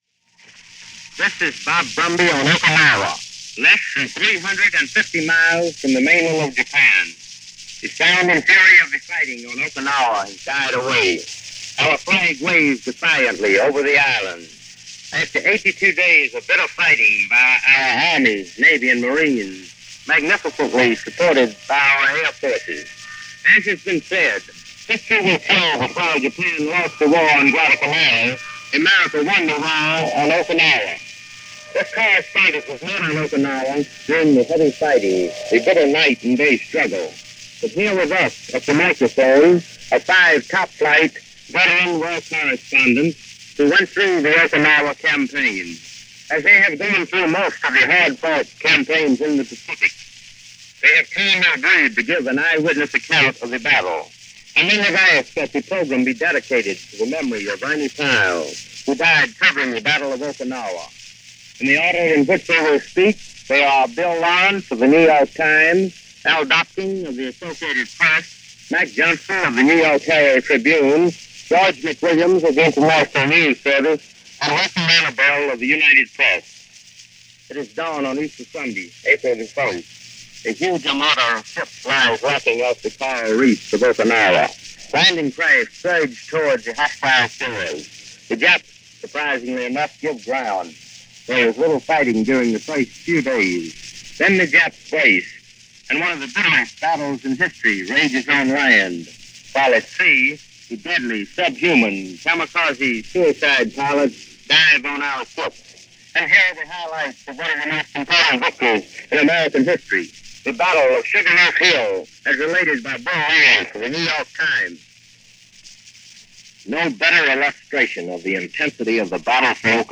June 24, 1945 - The Battle Of Okinawa - For the Most part over . . . - reports and a discussion by reporters of the previous 80 days for Mutual.